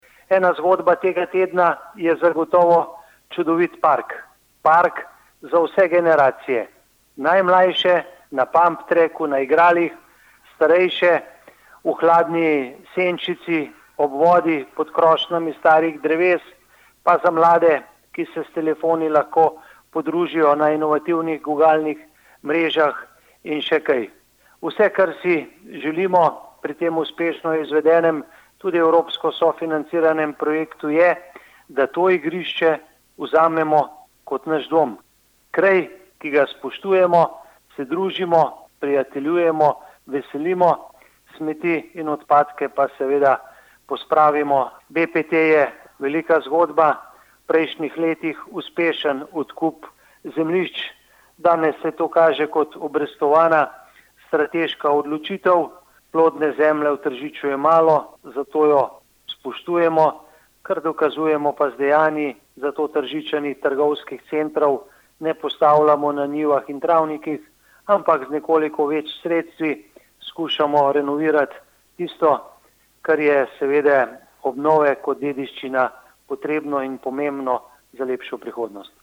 izjava_mag.borutsajoviczupanobcinetrzic_parkbpt.mp3 (1,9MB)